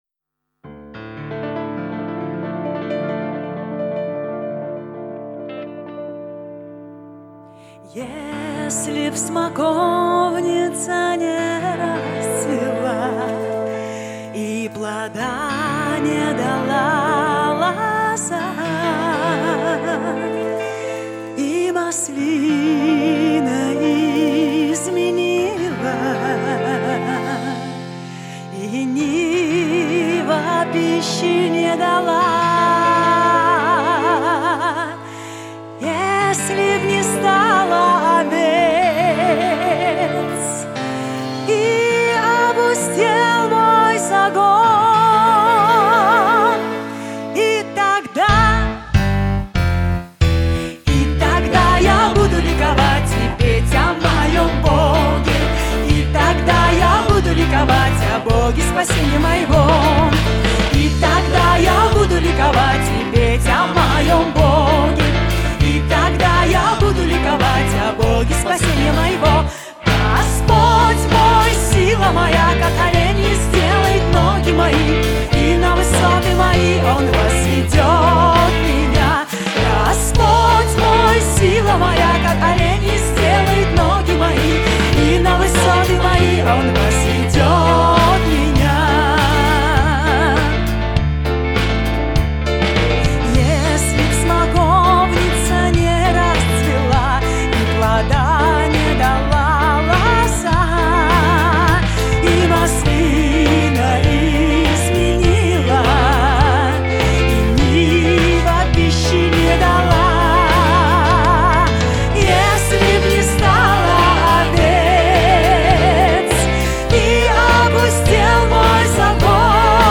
634 просмотра 613 прослушиваний 34 скачивания BPM: 106